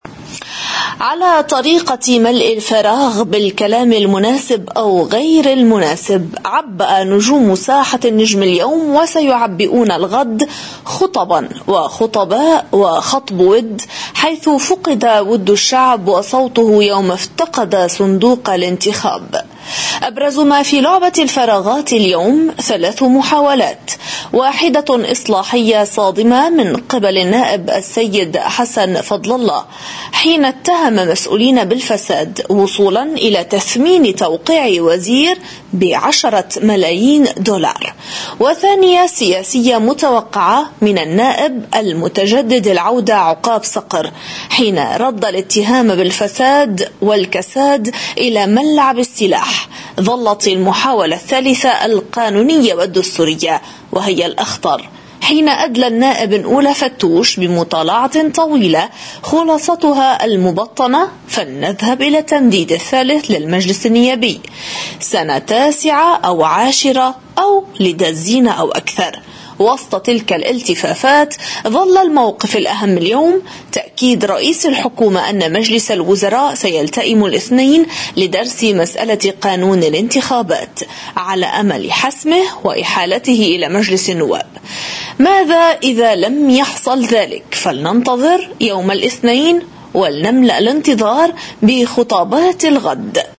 مقدمة نشرة قناة الـ”OTV” المسائية ليوم الخميس 6 نيسان 2017: